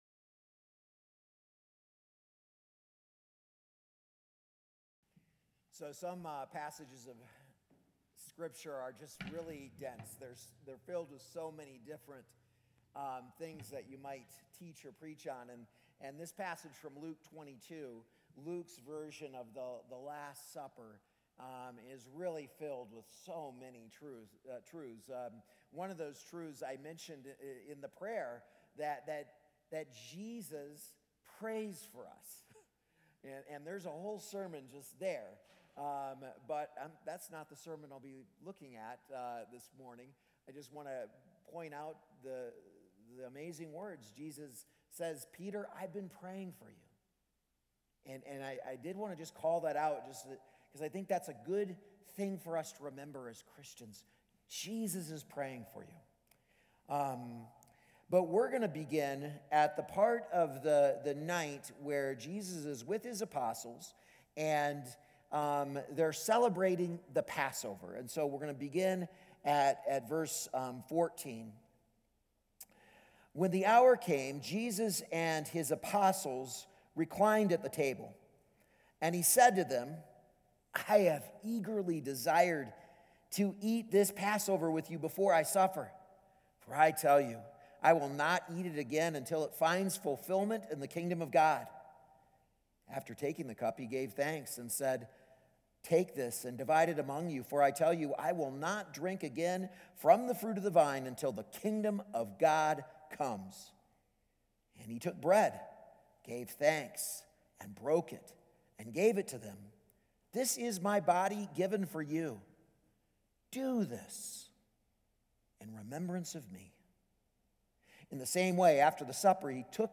A message from the series "Encountering the Cross."